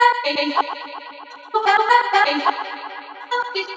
• chopped vocals ping pong house delayed (5) - Bm - 127.wav
chopped_vocals_ping_pong_house_delayed_(5)_-_Bm_-_127_OAN.wav